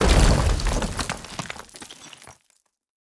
Destroyed Sound.ogg